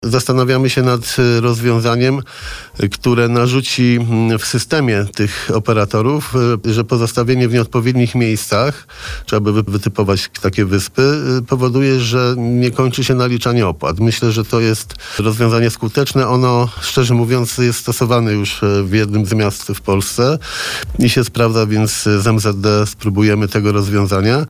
– Nie można pozostawiać [tych pojazdów – red.] na środku chodnika. W jednej lokalizacji nie może być czterech hulajnóg. Nie można zostawiać tych hulajnóg w miejscach przystanków. Jest zakazana jazda i zostawianie w obszarze starówki i pl. Ratuszowego – wyliczał na naszej antenie prezydent miasta, Jarosław Klimaszewskiego.